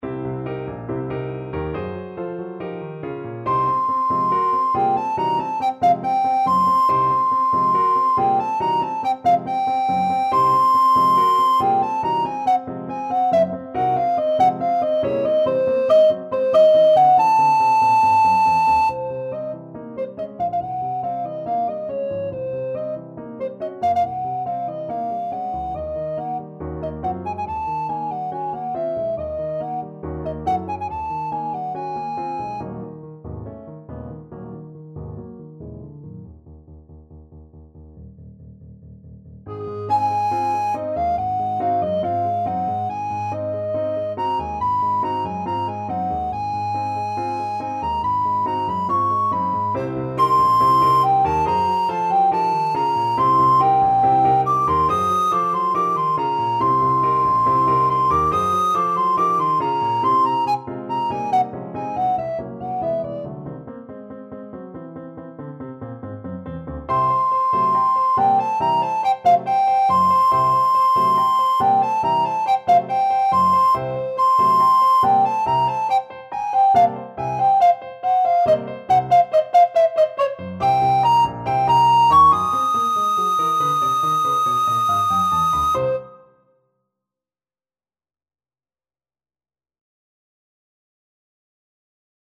4/4 (View more 4/4 Music)
Fast and Forceful = c. 140
Alto Recorder  (View more Intermediate Alto Recorder Music)
Jazz (View more Jazz Alto Recorder Music)